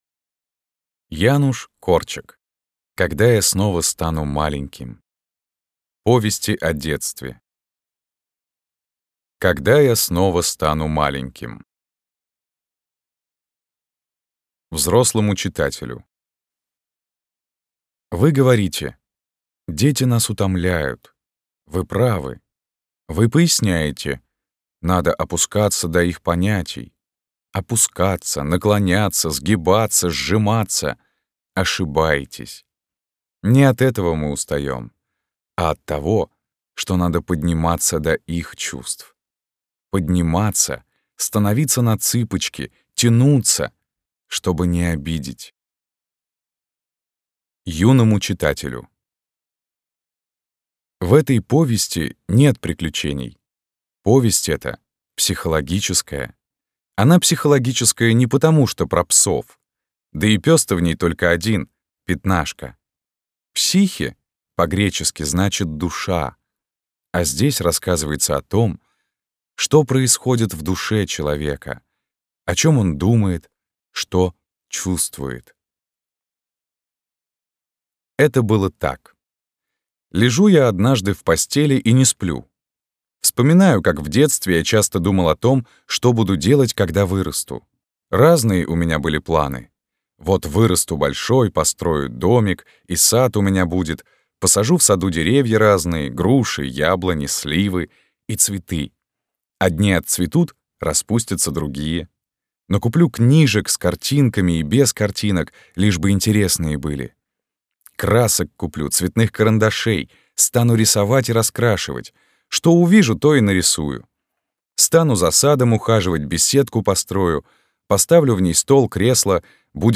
Аудиокнига Когда я снова стану маленьким. Повести о детстве | Библиотека аудиокниг